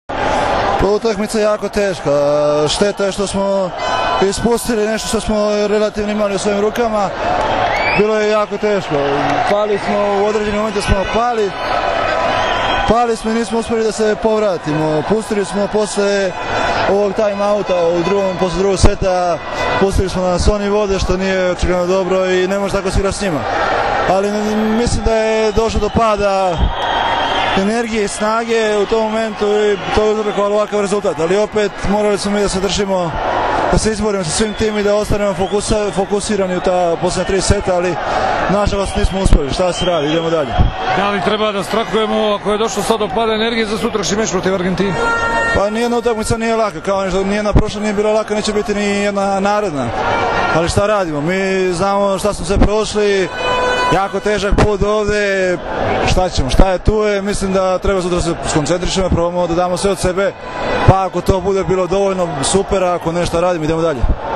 IZJAVA MARKA IVOVIĆA